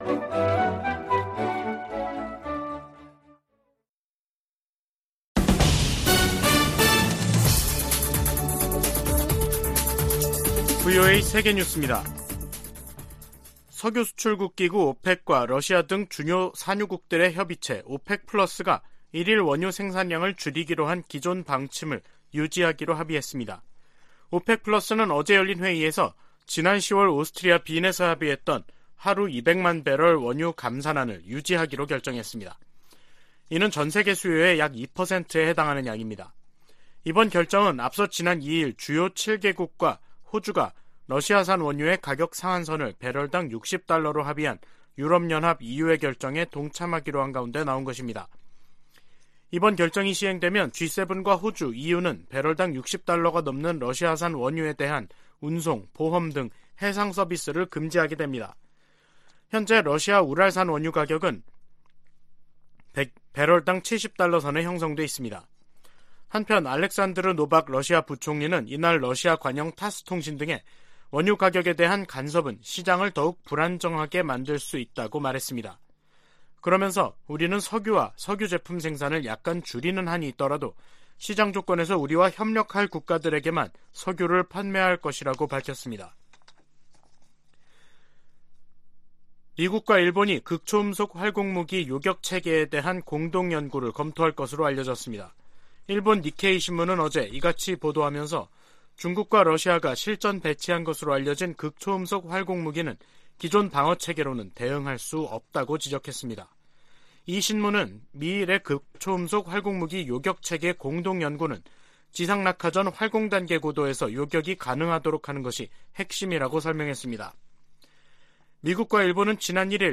VOA 한국어 간판 뉴스 프로그램 '뉴스 투데이', 2022년 12월 5일 3부 방송입니다. 북한이 오늘 동해와 서해상으로 남북 군사합의를 위반하는 무더기 포 사격을 가했습니다. 백악관 고위 당국자가 북한의 핵 기술과 탄도미사일 역량 발전이 누구의 이익에도 부합하지 않는다면서 중국과 러시아에 적극적인 제재 동참을 촉구했습니다.